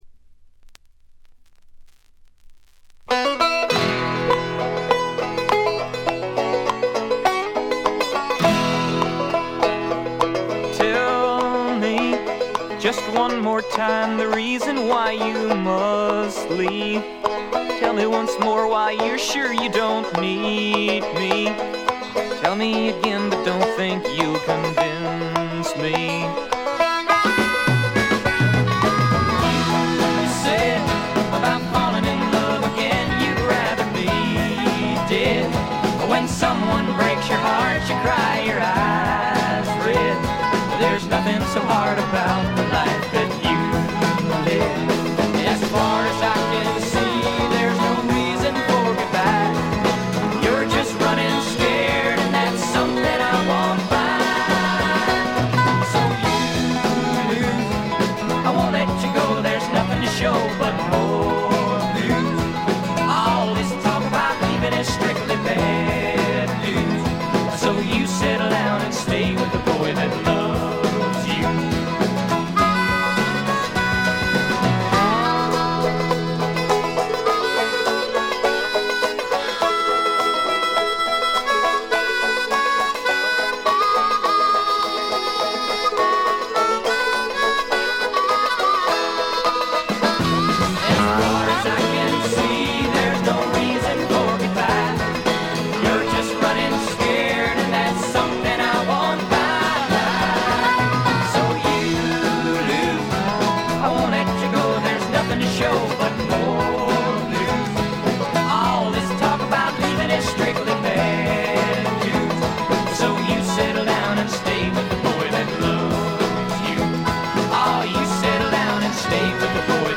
部分試聴ですが、チリプチ少々。
試聴曲は現品からの取り込み音源です。